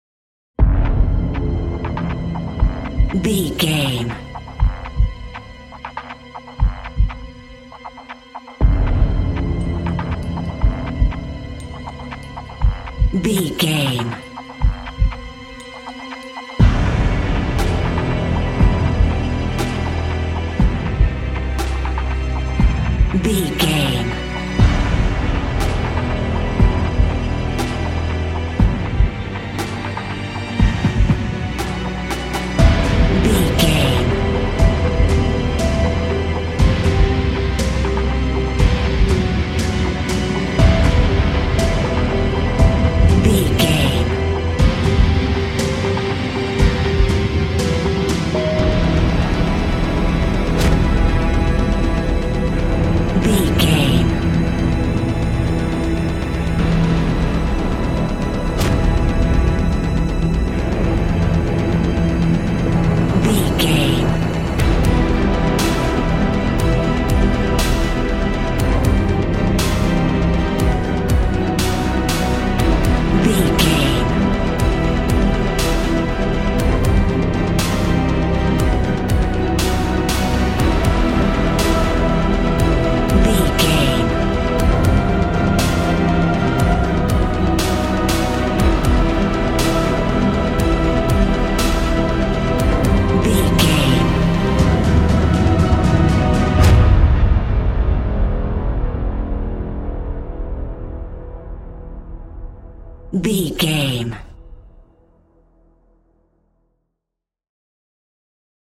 Epic / Action
Fast paced
In-crescendo
Ionian/Major
powerful
energetic
brass
violin
cello
piano
strings